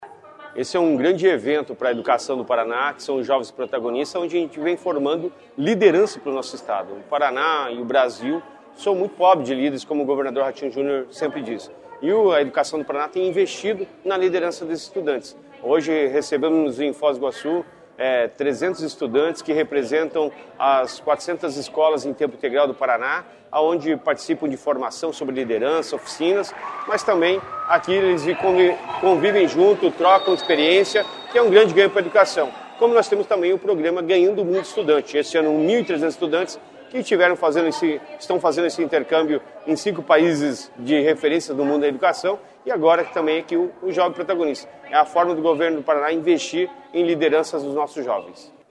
Sonora do secretário Estadual da Educação, Roni Miranda, sobre o quarto Encontro de Jovens Protagonistas